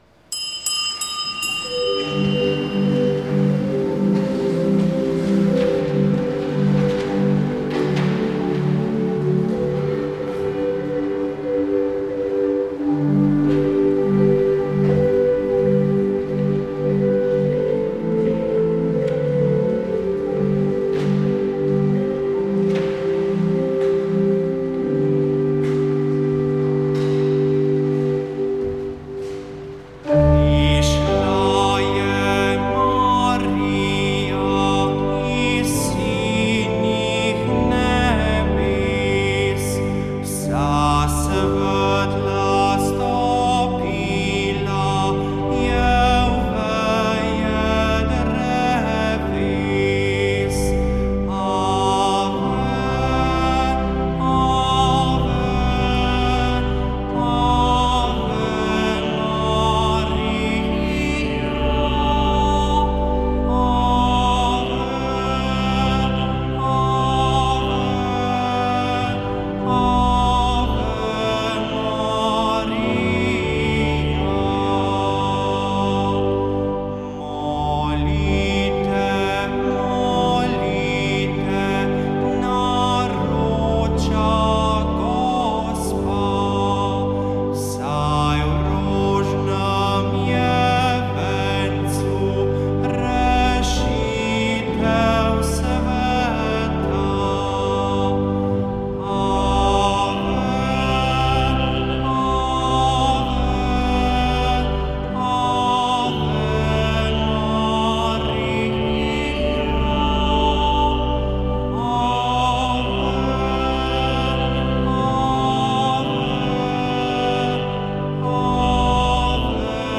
Sveta maša
Sv. maša iz bazilike Marije Pomagaj na Brezjah 27. 5.